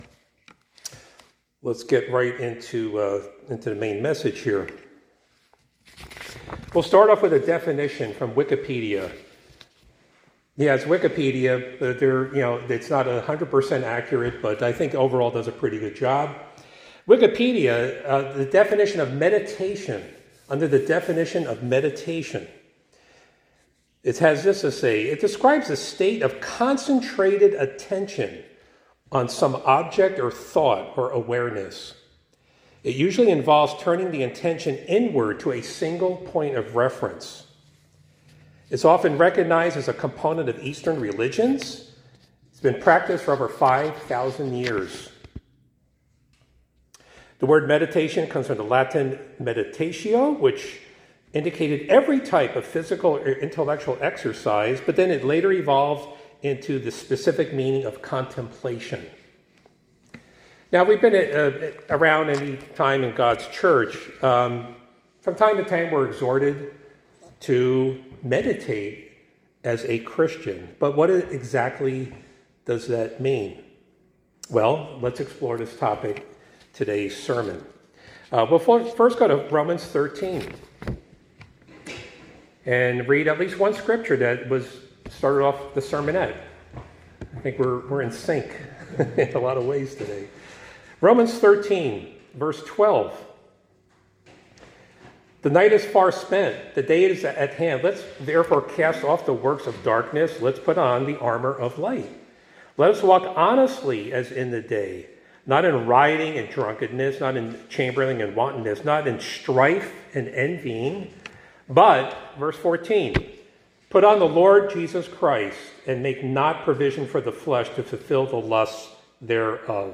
Sermons
Given in Hartford, CT